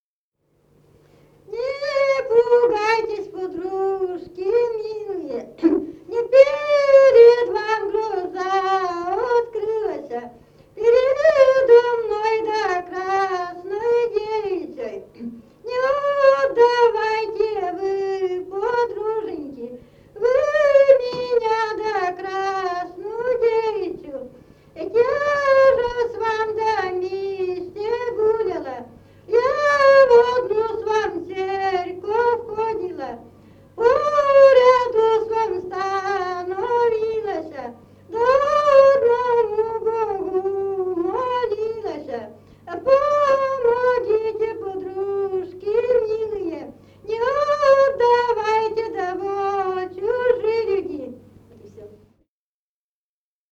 в д. Малата Череповецкого района